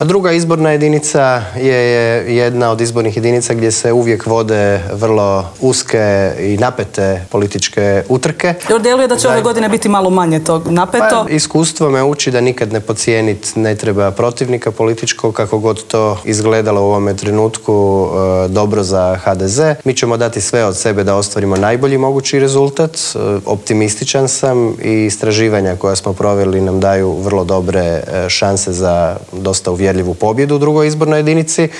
ZAGREB - Dan nakon raspuštanja 10. saziva Sabora i uoči odluke predsjednika Zorana Milanovića da će se parlamentarni izbori održati u srijedu 17. travnja, u Intervjuu tjedna Media servisa gostovao je predsjednik Sabora Gordan Jandroković.